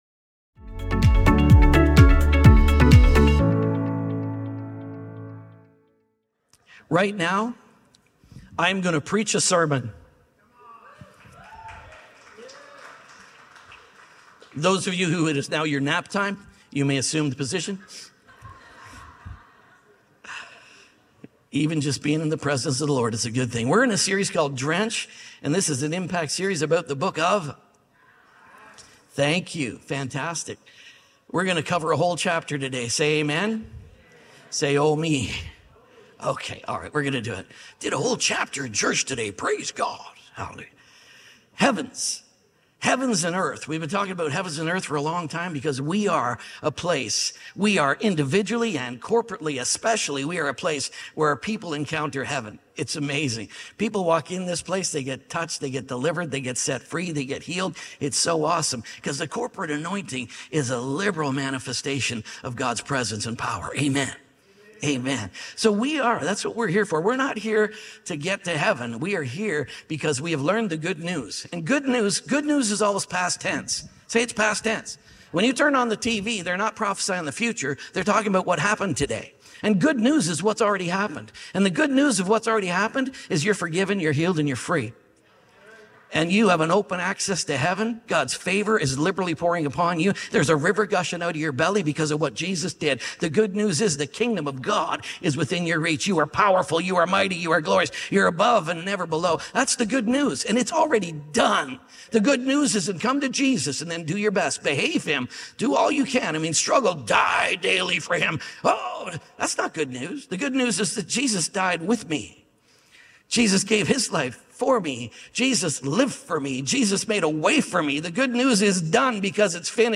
Riot To Rival | Drench Series | SERMON ONLY.mp3